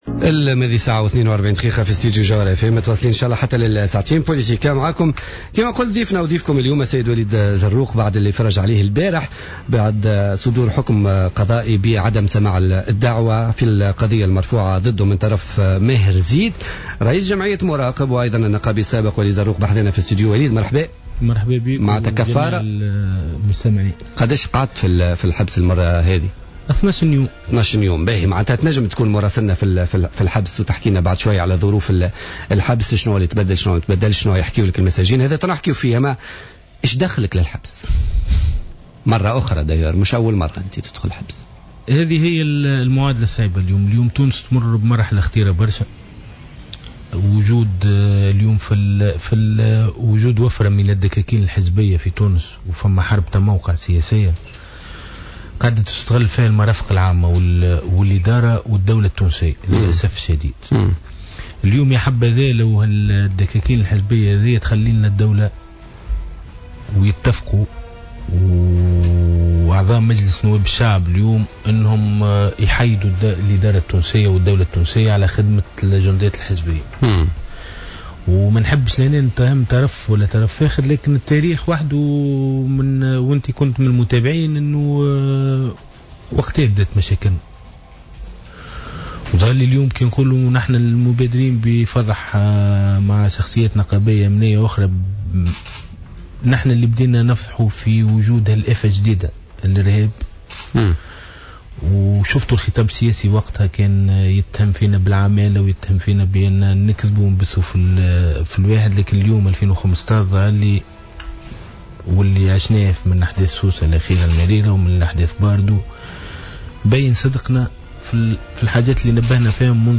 وأكد في مقابلة على "جوهرة أف أم" اليوم في برنامج "بوليتيكا" أن هناك متهمين بالتواطؤ مع الإرهاب في مناصب عليا للدولة من بينهم متهمين وجهت إليهم تهم التورط في قضية اغتيال النائب محمد البراهمي.